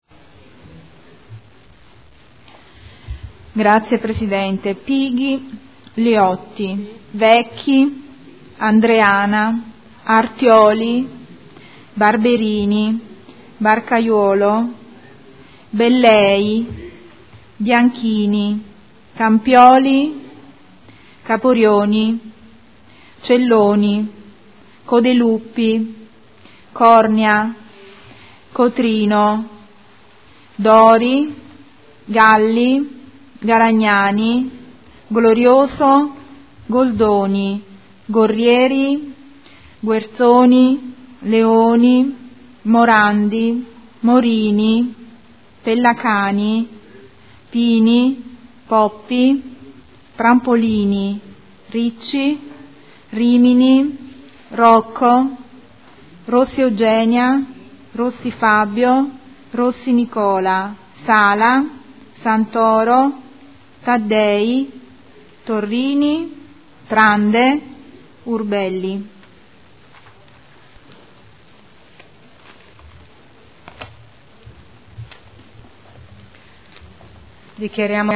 Seduta del 18/02/2013. Appello